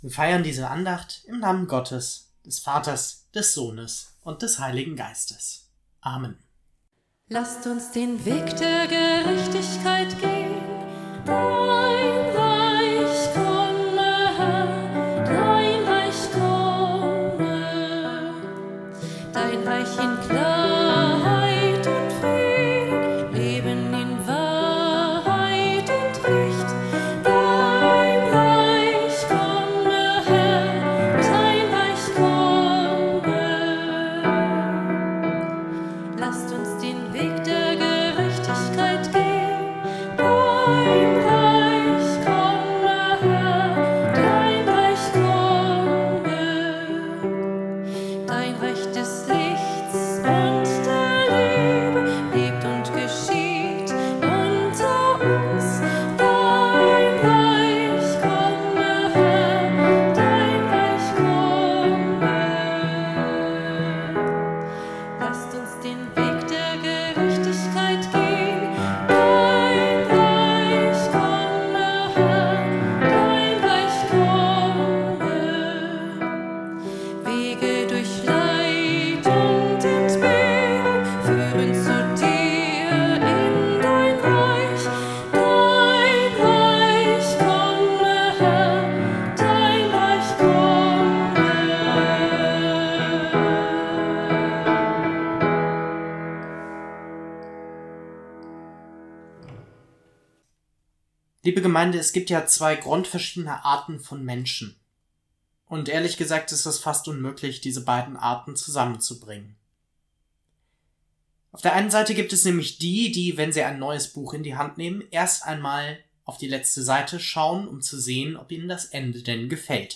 Der Predigttext erzählt von Josef, der eine ganz ähnliche Fähigkeit hat.